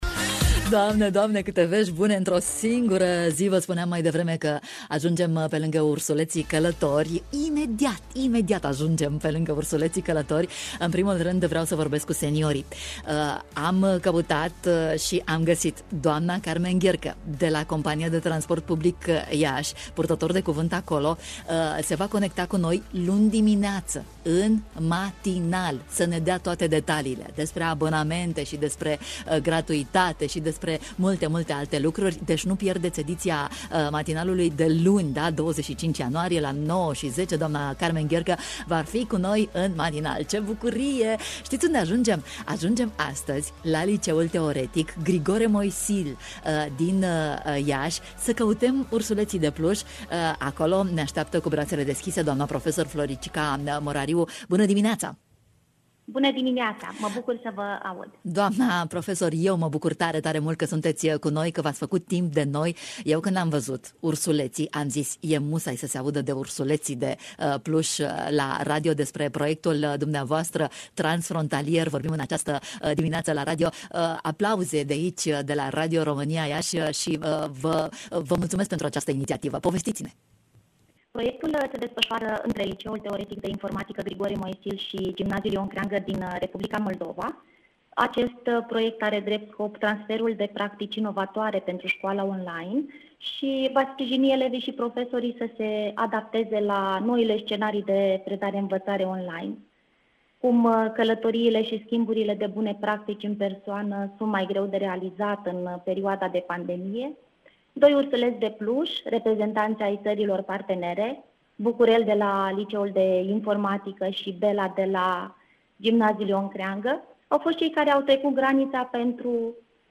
În această dimineaţă, a stat de vorbă cu noi